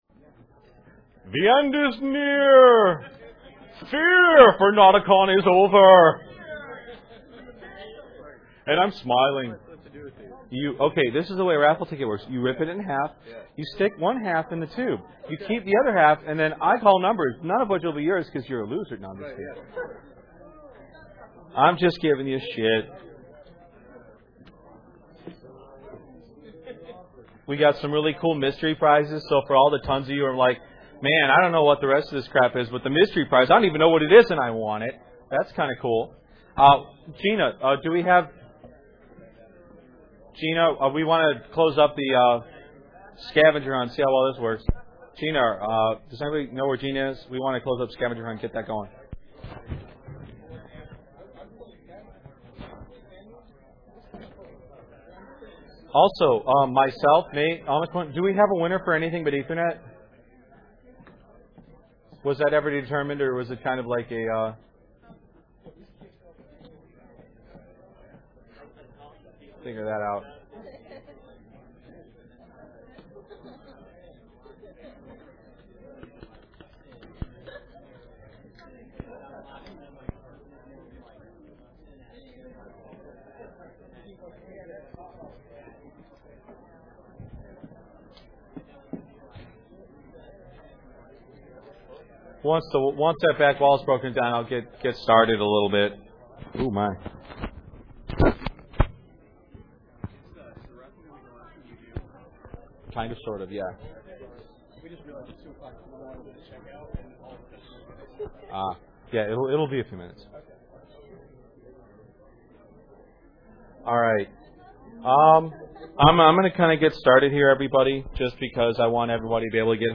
notacon-2006-ClosingCeremonies.mp3